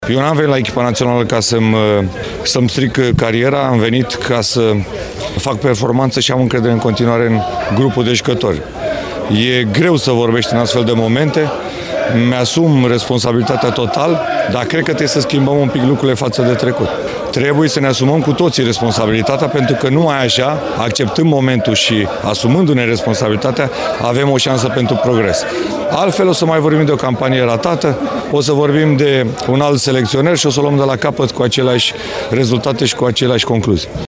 La sesiunea de declarații de la Prima TV, căpitanul Vlad Chiricheș și selecționerul Edward Iordănescu și-au asumat responsabilitatea rușinosului rezultat: